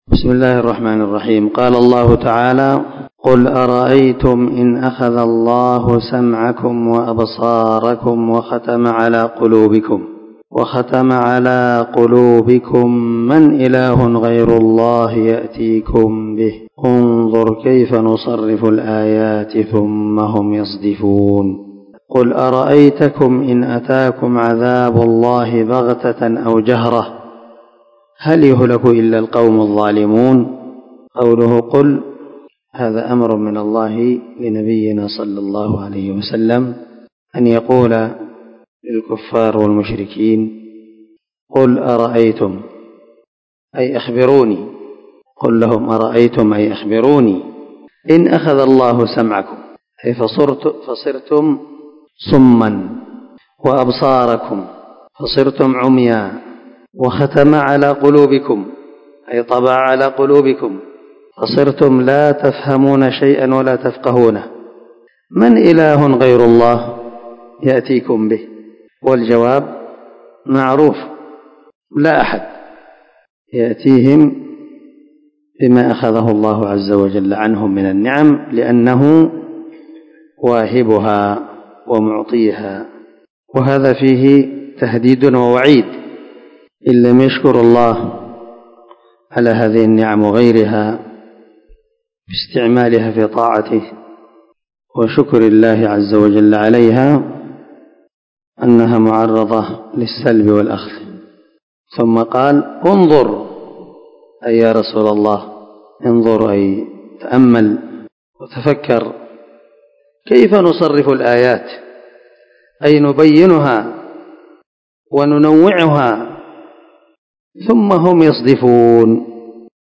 404الدرس 12 تفسر آية ( 46 – 49 ) من سورة الأنعام من تفسير القران الكريم مع قراءة لتفسير السعدي